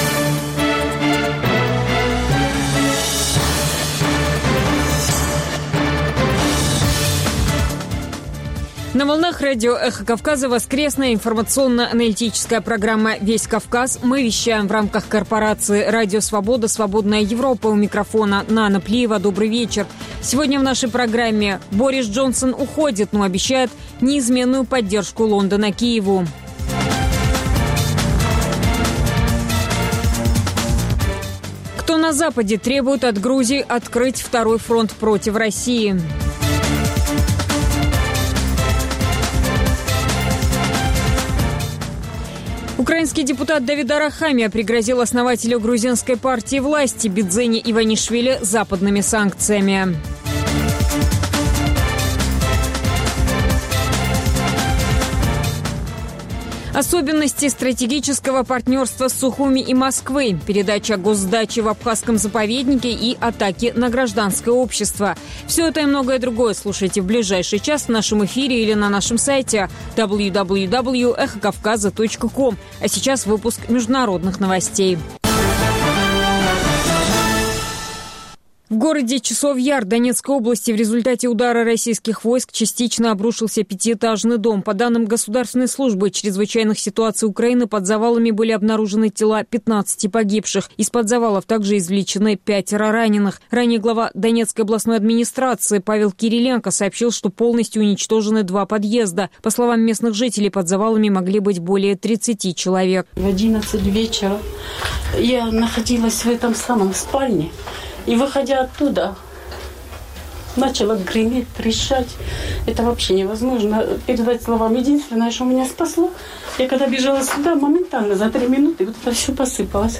Новости, репортажи с мест, интервью с политиками и экспертами, круглые столы, социальные темы, международная жизнь, обзоры прессы, история и культура.